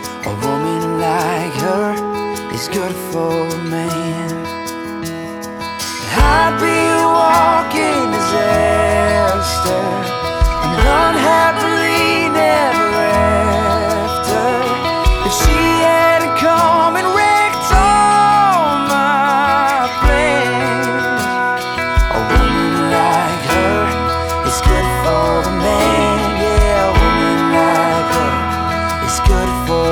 Genre: Rock